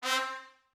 Horns and Brass
campfire horns.wav